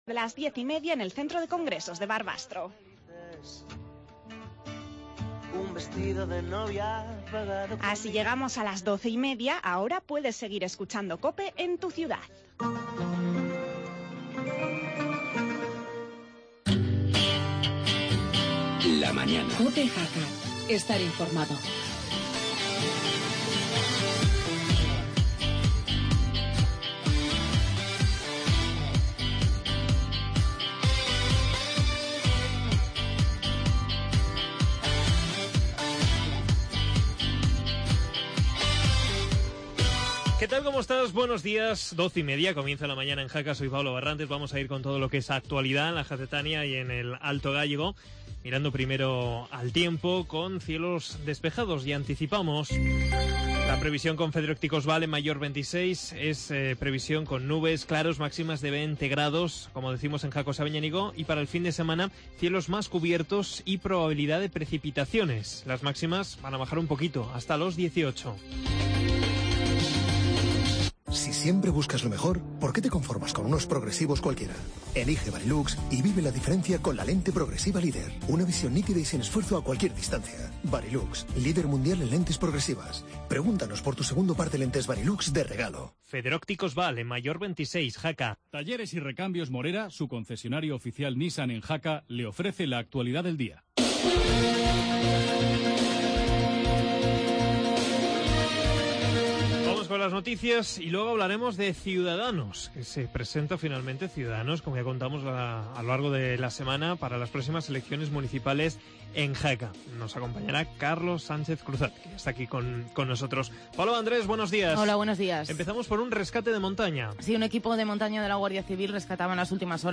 Actualidad, entrevista